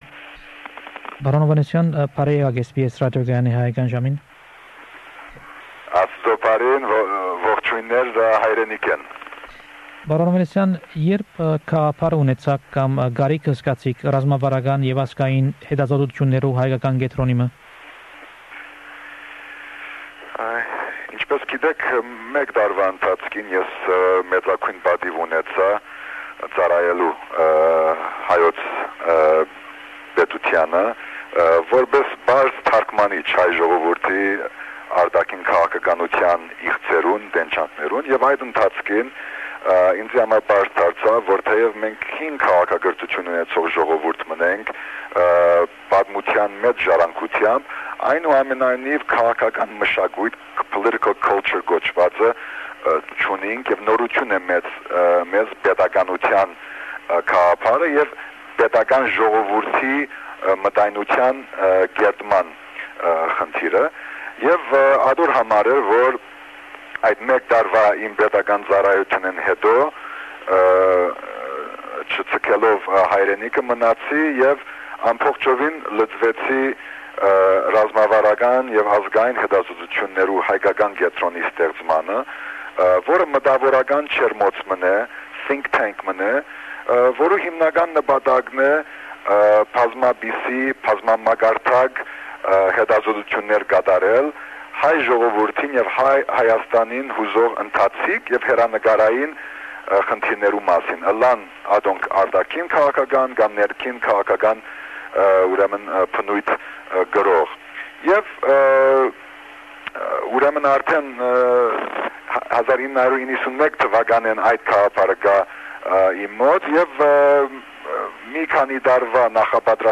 Its August 1996 and Armenia's first foreign minister Raffi Hovhannisian talks with SBS Armenian program, from Yerevan, about his views and his work to establish a democratic Armenia. He sounds very enthusiastic about the future of Armenia. (Interview in Armenian)